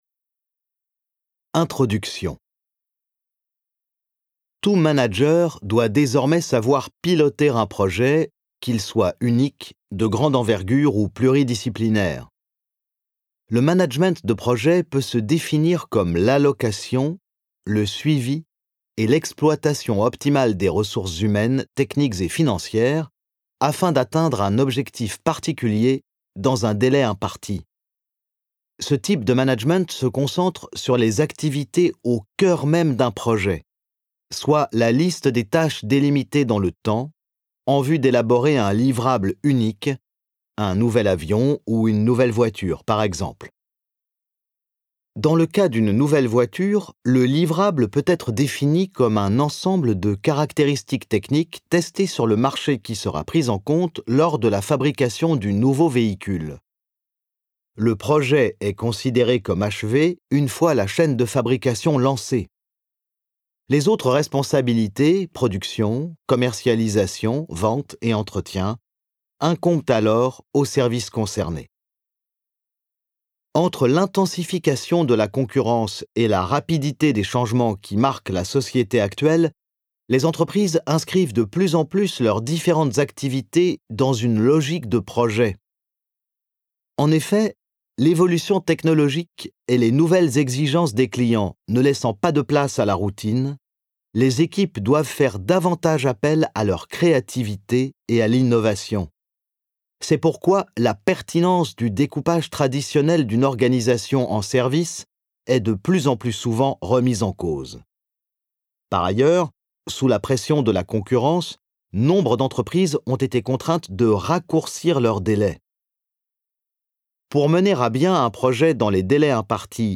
je découvre un extrait - L'essentiel pour manager un projet de Robert D. Austin, Harvard Business Review, Richard Luecke